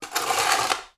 SPADE_Scrape_Asphalt_RR1_mono.wav